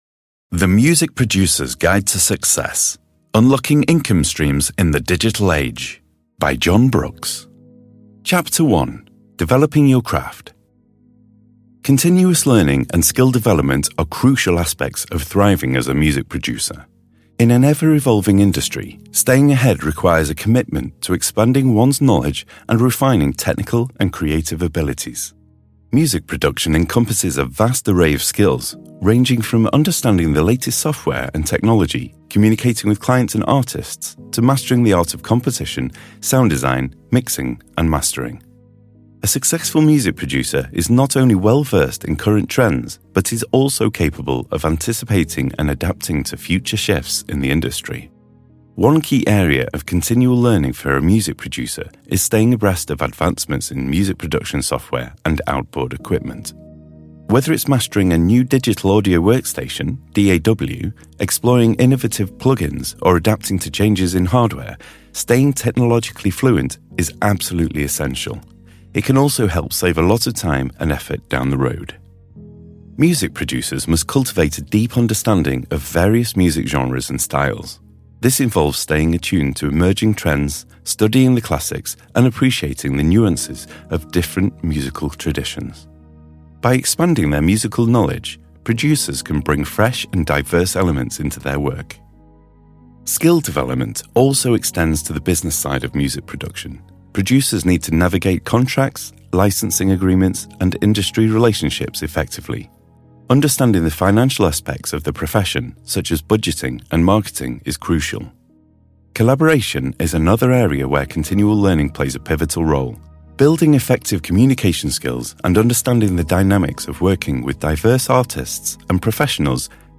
(AudioBook)